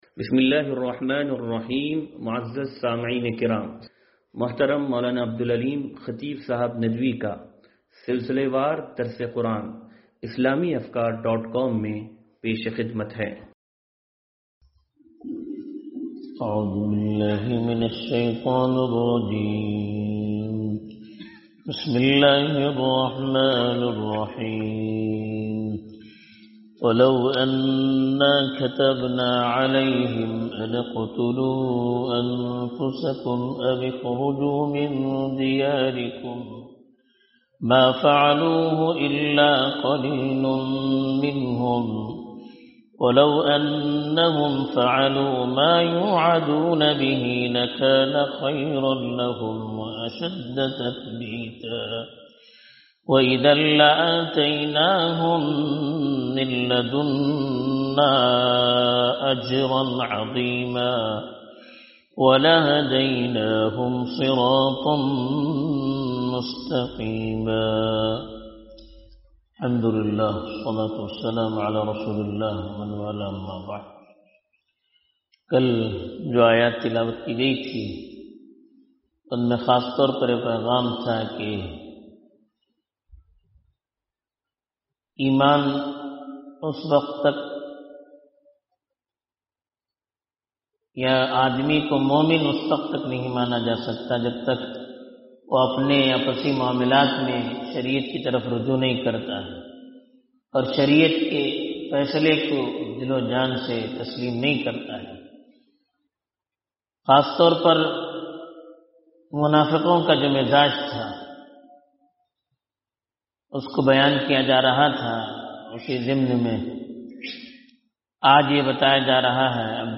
درس قرآن نمبر 0364